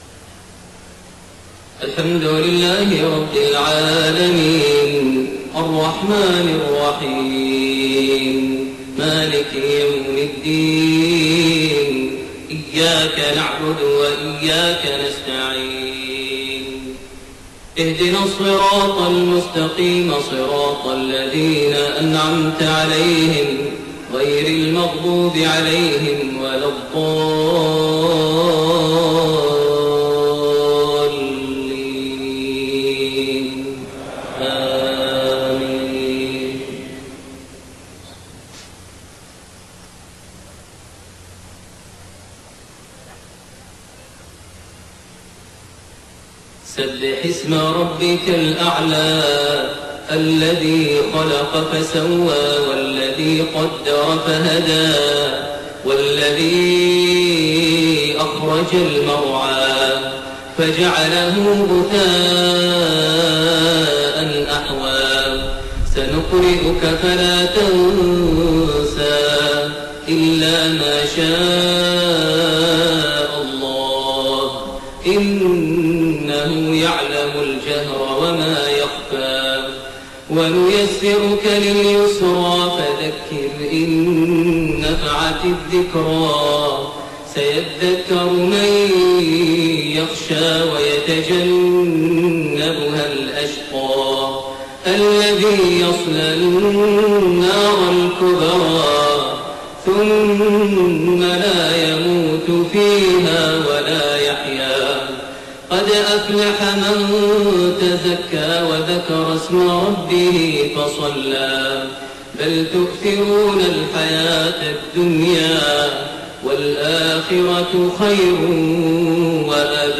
Maghrib prayer from Surat Al-A'laa and Az-Zalzala > 1431 H > Prayers - Maher Almuaiqly Recitations